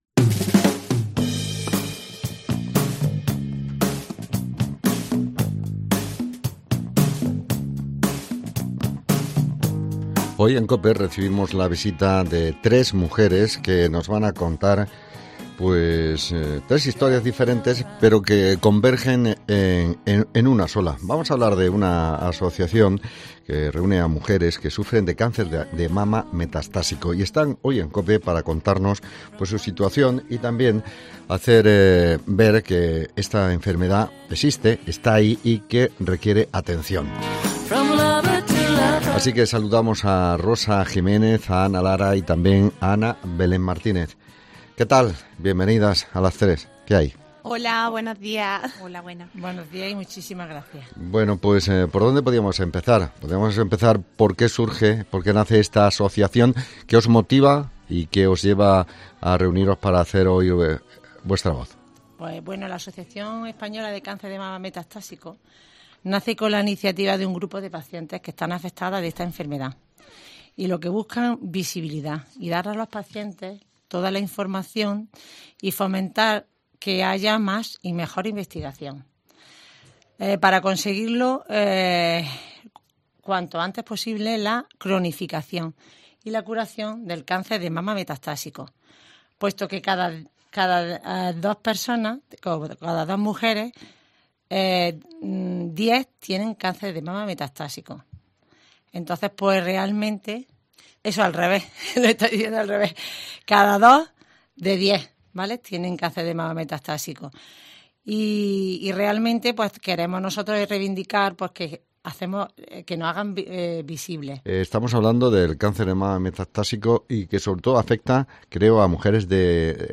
Entrevistamos a 3 mujeres de Jaén que forman parte de la asociación que nace de la iniciativa de un grupo de pacientes afectadas por esta enfermedad, el cáncer de mama metastásico